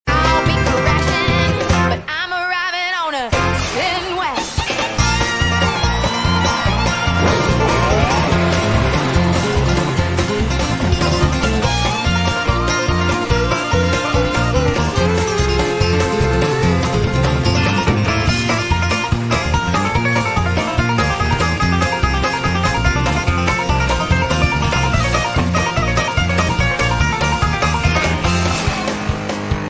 I couldn't do it - so I am playing it on hard difficulty.